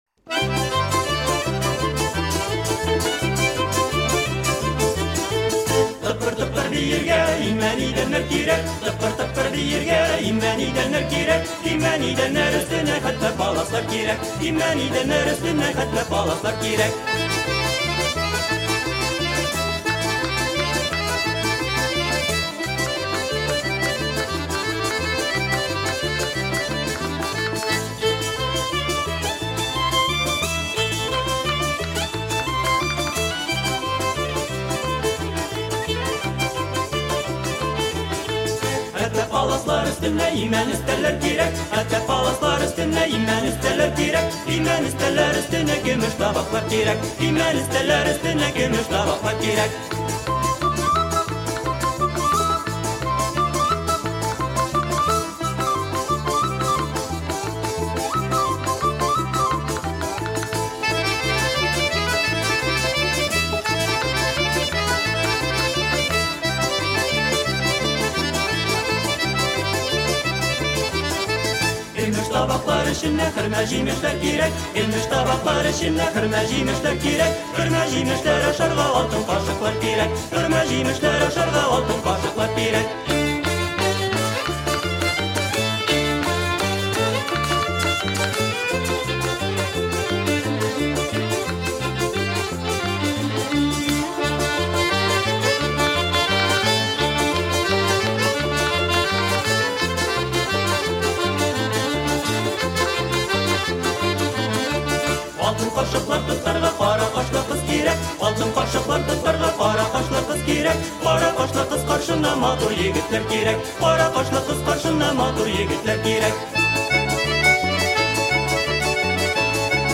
Сегодня у нас песня-частушка – такмаклар. Очень зажигательная и весёлая!